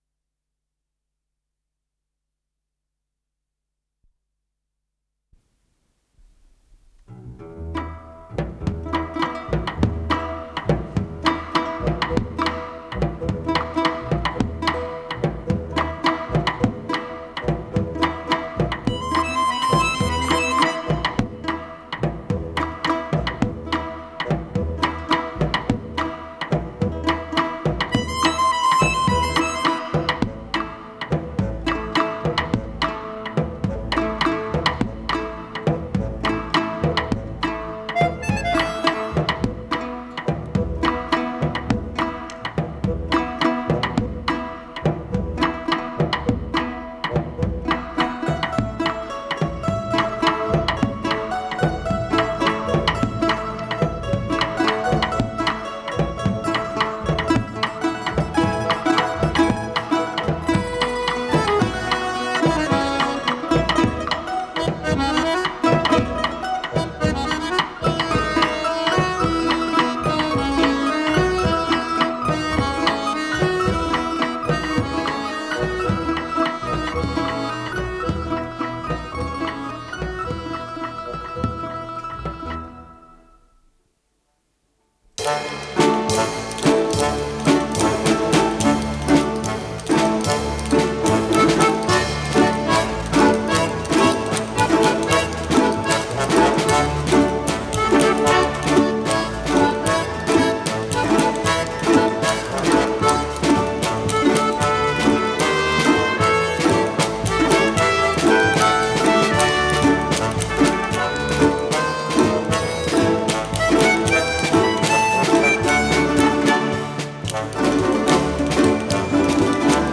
с профессиональной магнитной ленты
Содержание5. Индийский танец
6. Чилийский танец
7. Кубинский танец
ВариантДубль моно